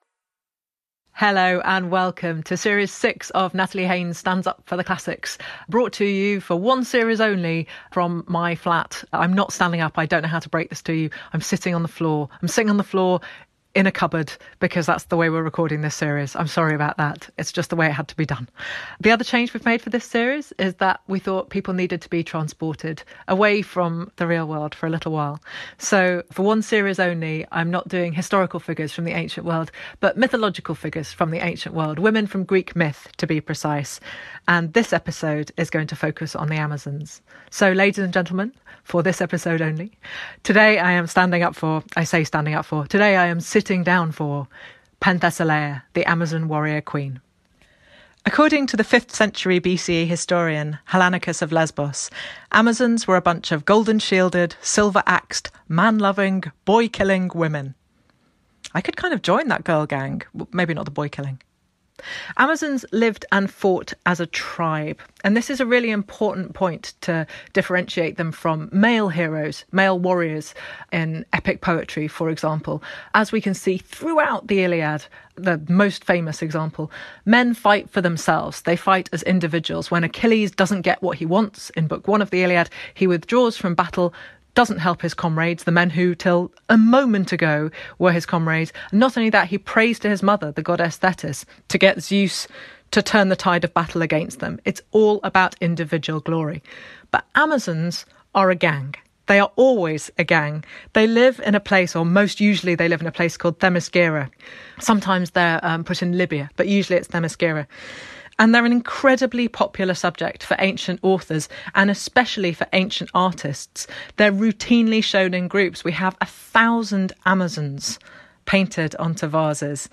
An archive of Natalie Haynes' stand-up monologues about people from ancient Greece and Rome